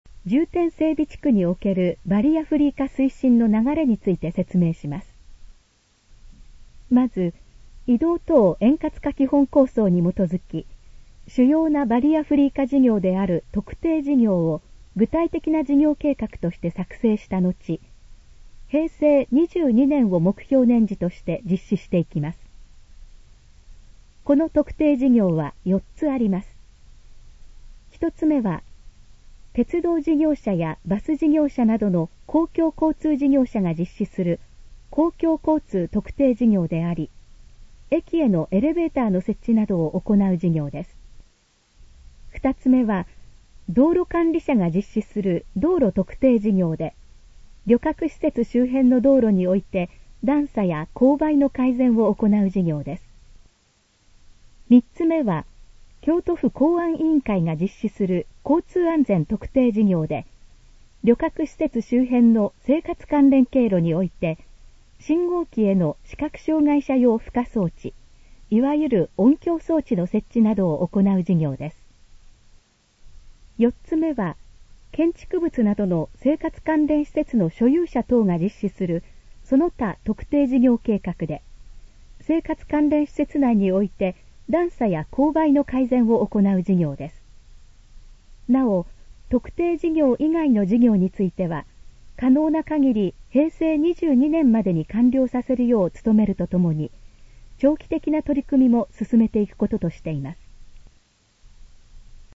このページの要約を音声で読み上げます。
ナレーション再生 約400KB